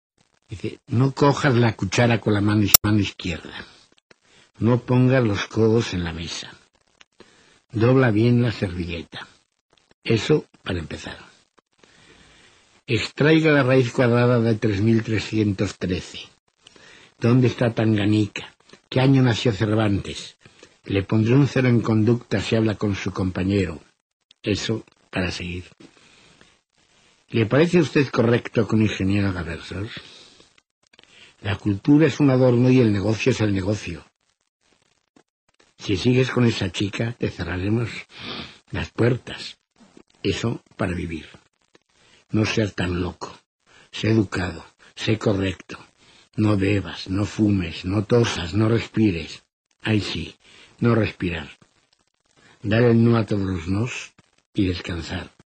Voz del propio autor